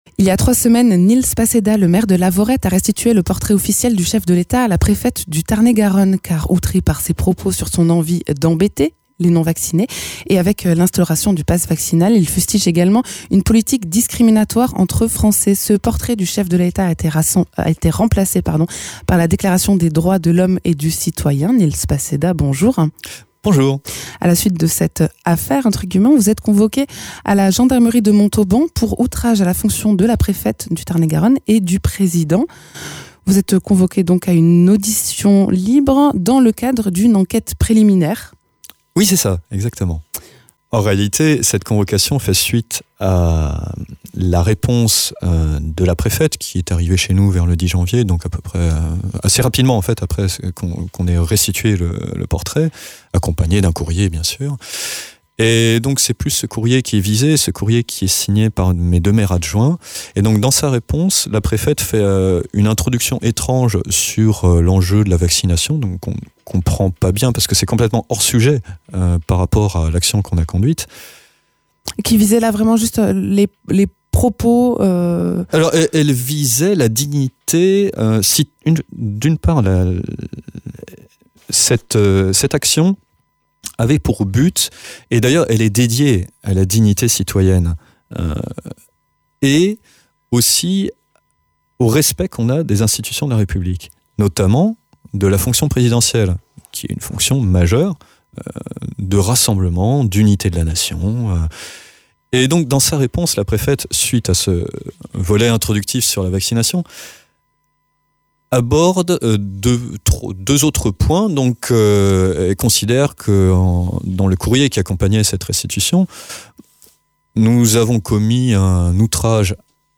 Interviews
Invité(s) : Nils Passedat, maire de Lavaurette